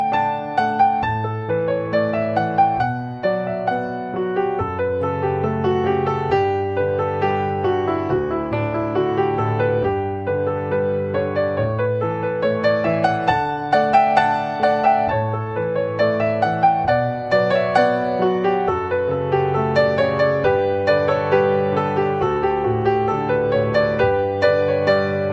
Ringtones Category: Instrumental